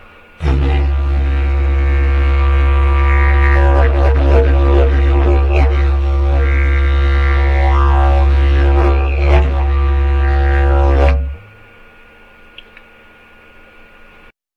MY DIDJERIDUs
Nine-stave redwood with laced leather bands each end, 5'-6" long, pitched in C#